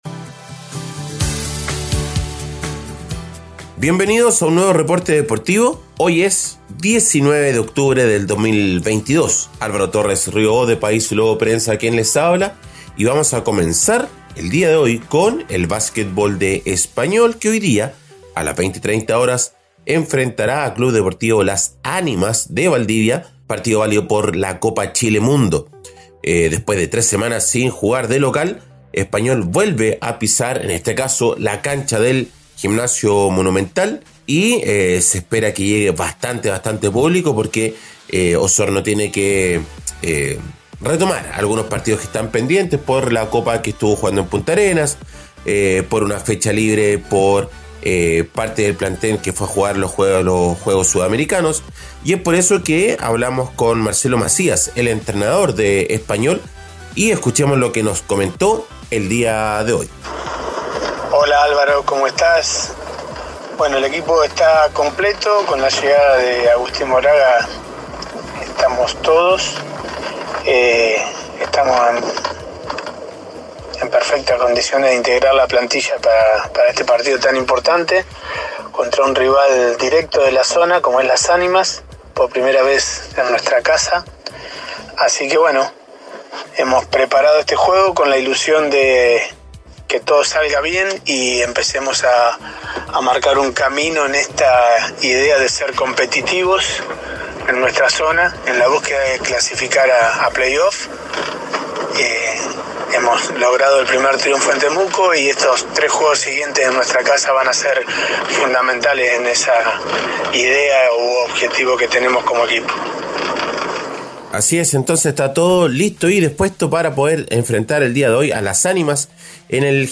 Reporte Deportivo 🎙 Podcast 19 de octubre de 2022